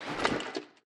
equip_gold3.ogg